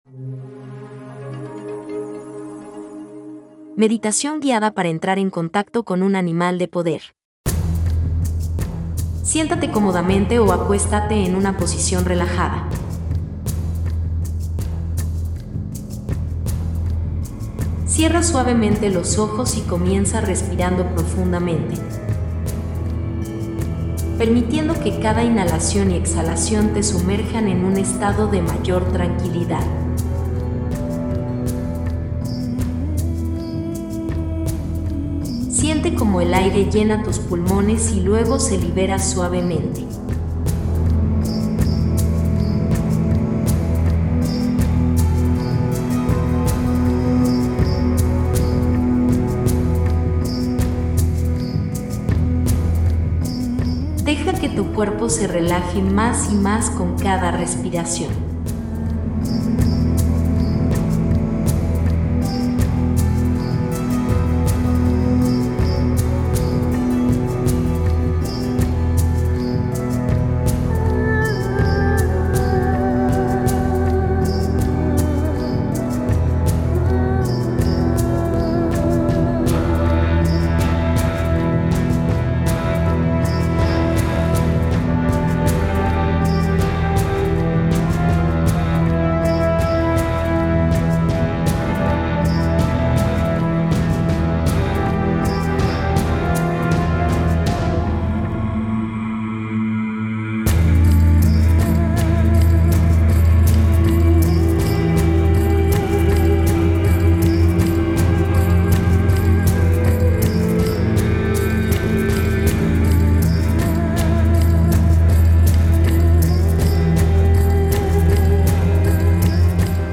Elige si prefieres escuchar el audio de la meditación o ver el video